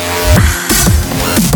no, they don't need to be ms-perfect, they can be slightly offset by a few ms in either direction (as would be the case when playing) and still cause noticeable distortion. i made some examples here: